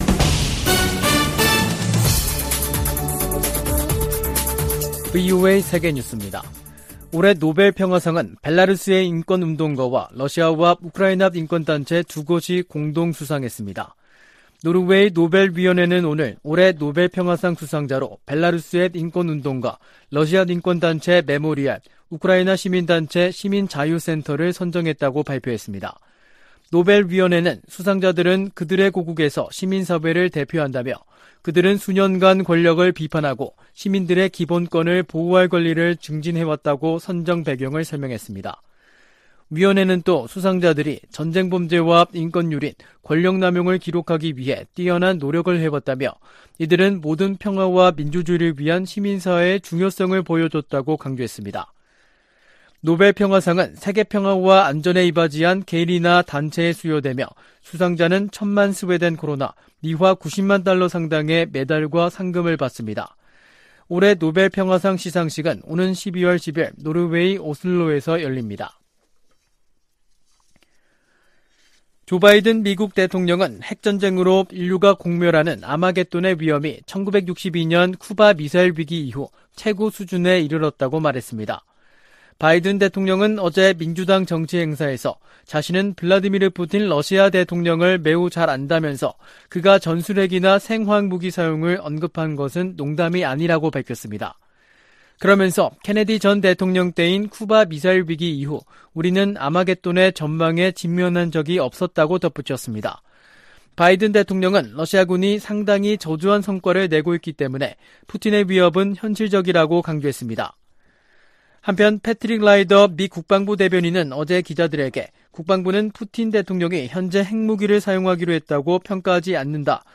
VOA 한국어 간판 뉴스 프로그램 '뉴스 투데이', 2022년 10월 7일 3부 방송입니다. 한국 정부는 북한이 7차 핵실험을 감행할 경우 9.19 남북 군사합의 파기를 검토할 수 있다고 밝혔습니다. 미국 정부는 북한의 탄도미사일 발사 등 도발에 대응해 제재를 포함한 여러 방안을 활용할 것이라고 밝혔습니다. 미 국방부는 북한의 최근 탄도미사일 발사에 대해 무책임하고 불안정한 행동을 즉시 중단할 것을 촉구했습니다.